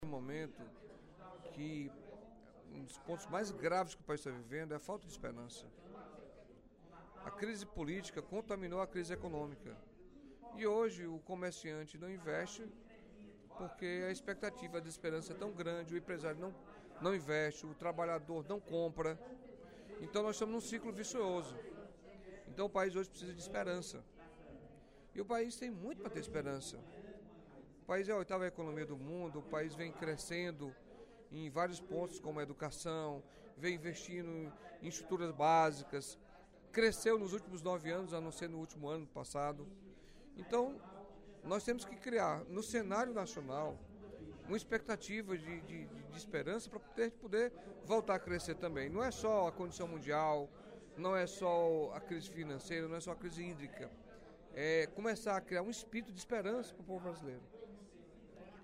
O deputado Carlos Felipe (PCdoB) fez, durante o primeiro expediente da sessão plenária desta quinta-feira (13/08), uma reflexão sobre o momento de descrença política e econômica que vive o País.